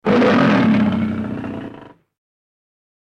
Animals
Lion(48K)